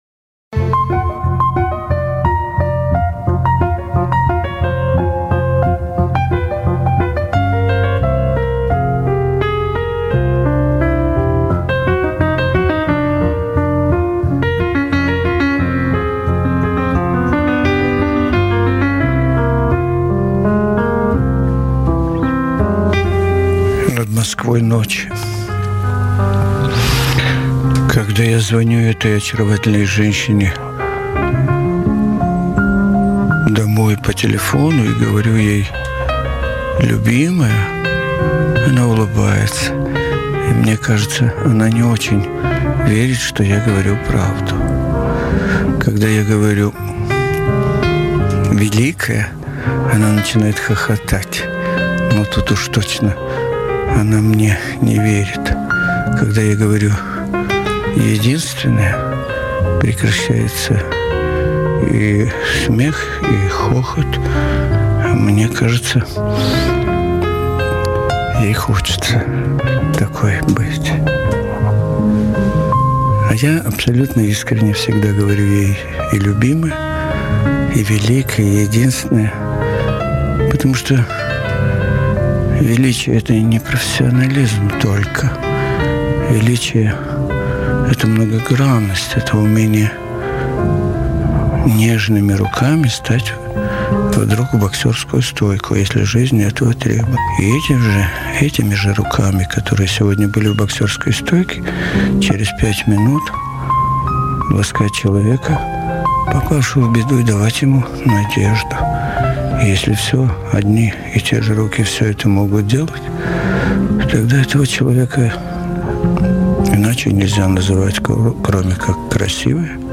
С ней беседует Николай Тамразов.
Беседа с Л.Рубальской